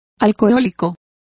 Complete with pronunciation of the translation of alcoholic.